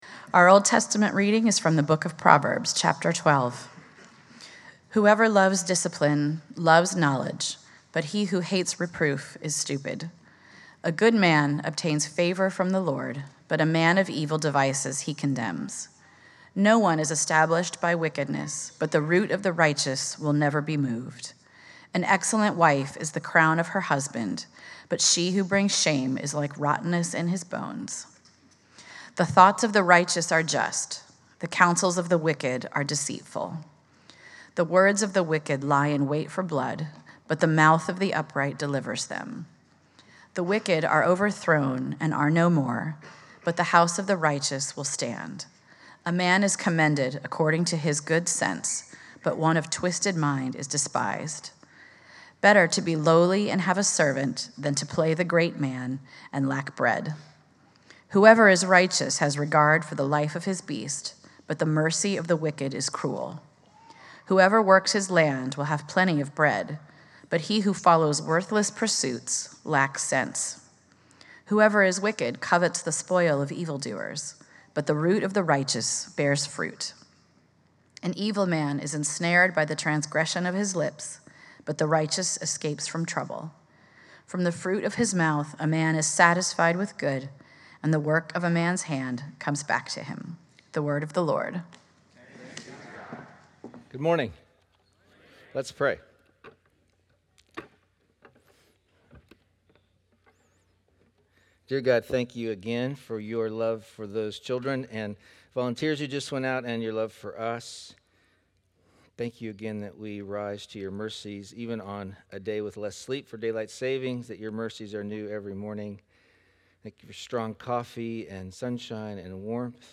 By examining Proverbs, the sermon challenges listeners to view their daily tasks as a seven-day mission and to understand their professional roles as just one of many important vocations in a life dedicated to Christ.